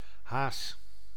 Ääntäminen
IPA: [fi.lɛ]